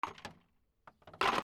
空き瓶のケースを積む
/ J｜フォーリー(布ずれ・動作) / J-14 ｜置く
『カチャ タン』